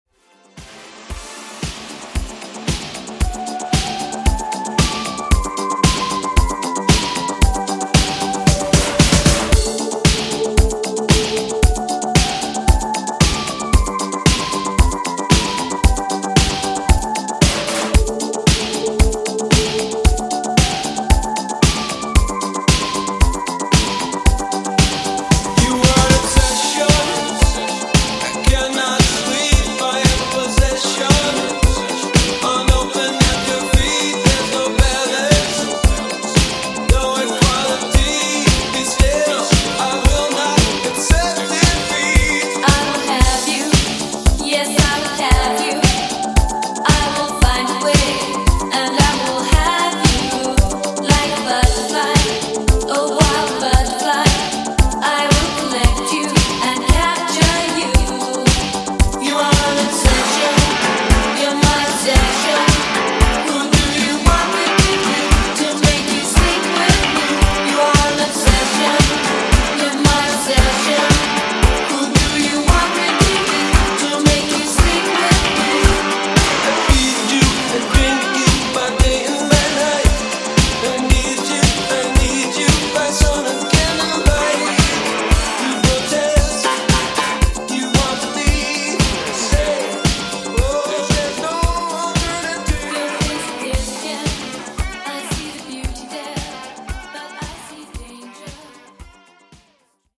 80s NewWave ReDrum)Date Added